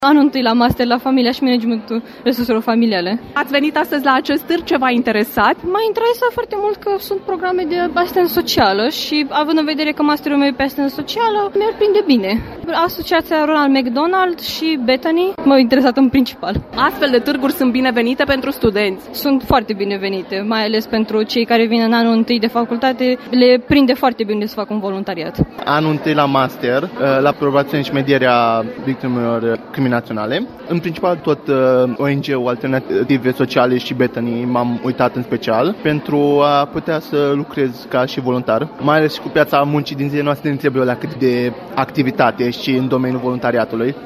Studenții care au venit, astăzi, la Târgul ofertelor de practică, voluntariat și internship în domeniul asistenței sociale spun că a face voluntariat reprezintă o oportunitate și o șansă pentru a găsi mai ușor un loc de muncă:
7-nov-vox-studenti.mp3